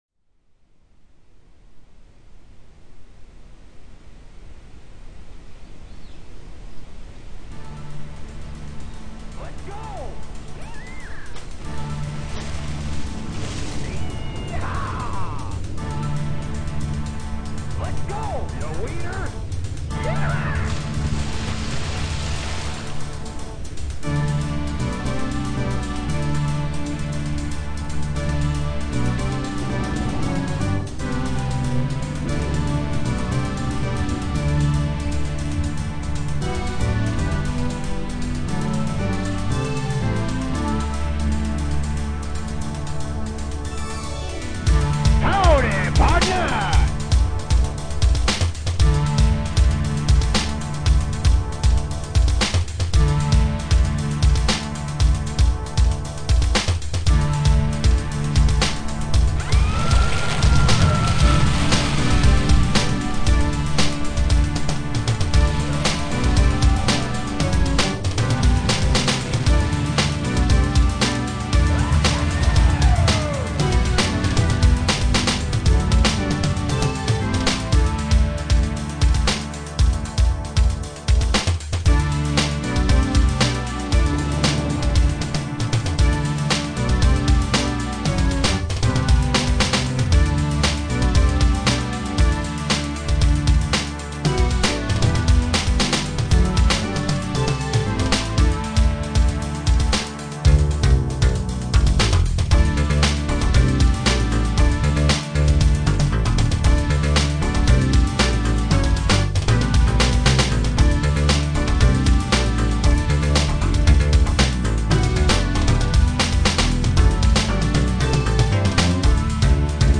Download 2,1Mb Tema fra spillet (del 1)